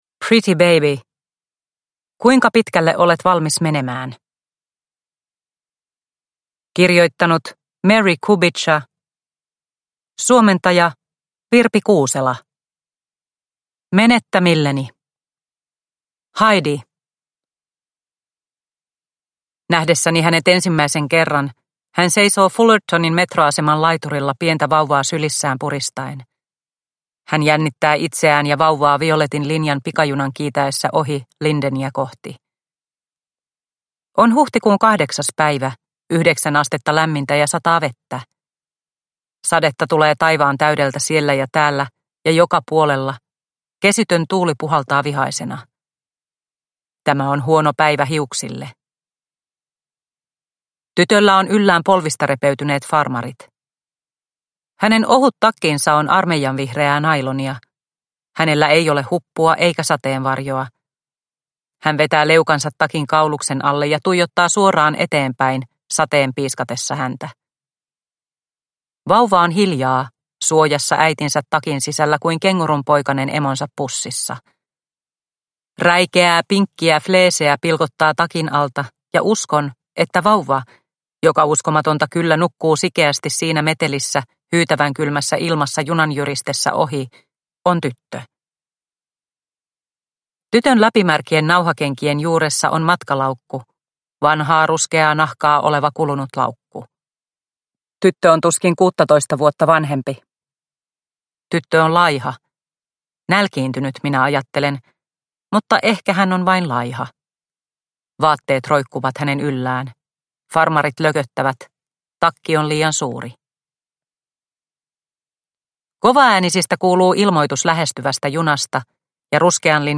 Pretty Baby – Ljudbok – Laddas ner